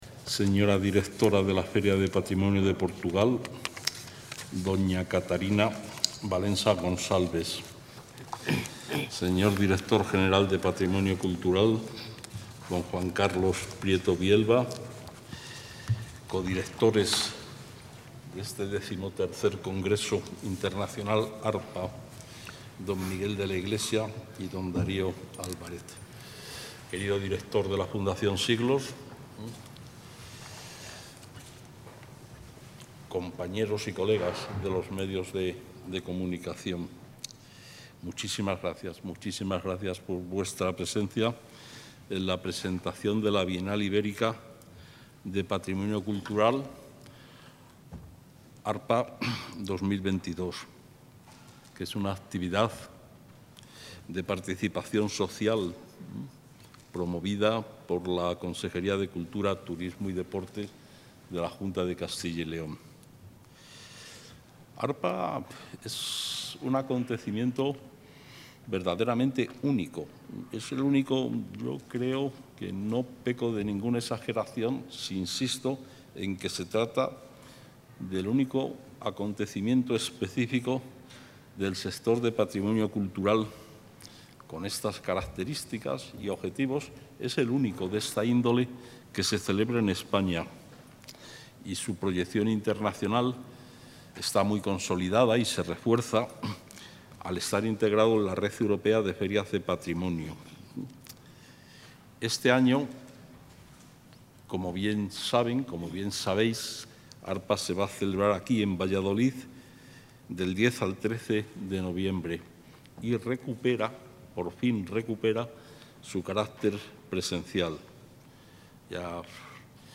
Intervención del consejero de Cultura, Turismo y Deporte.